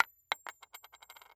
household
Coin Drop on Wood Platform 2